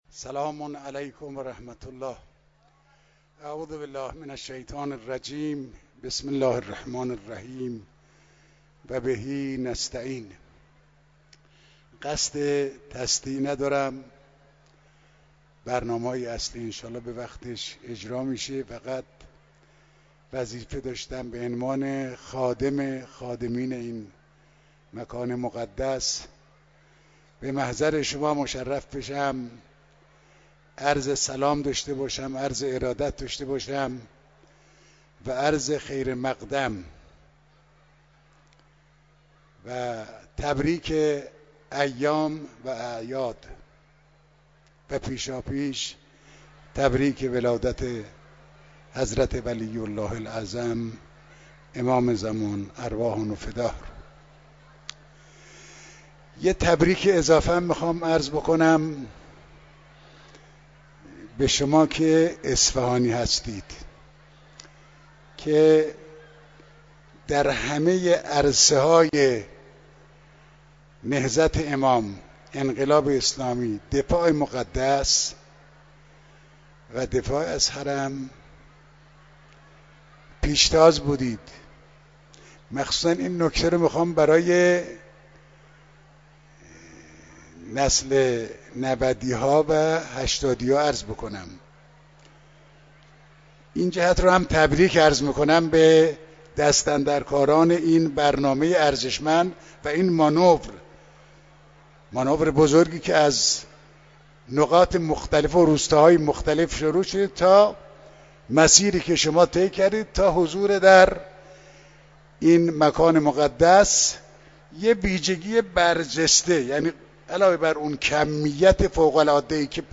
مراسم مسجد مقدس جمکران